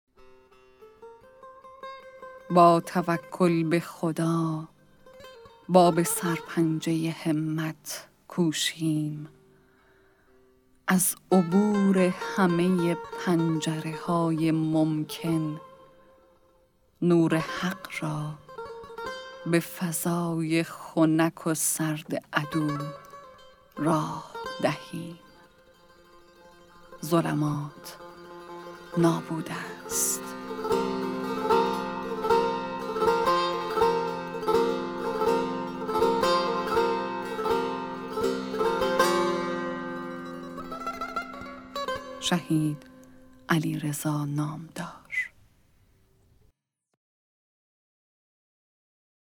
«شمال حماسه» کتاب صوتی اشعار شاعران شهید استان گیلان